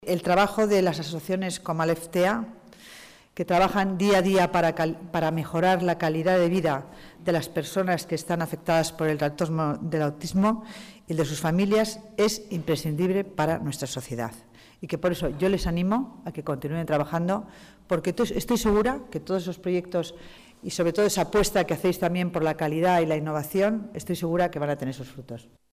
Nueva ventana:Declaraciones de la delegada de Familia y Servicios Sociales, Concepción Dancausa